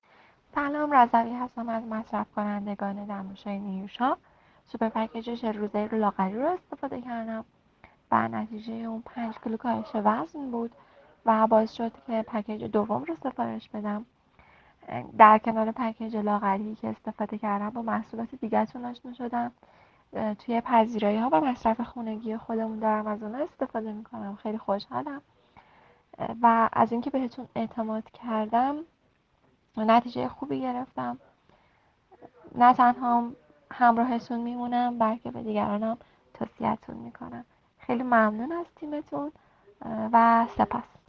کلیپ های صوتی مصرف کنندگان دمنوش لاغری نیوشا :